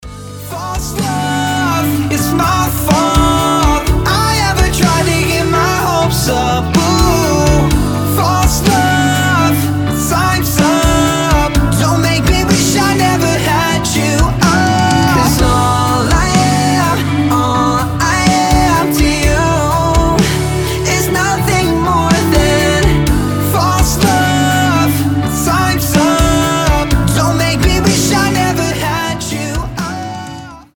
• Качество: 320, Stereo
красивый мужской голос
Post-Hardcore
баллады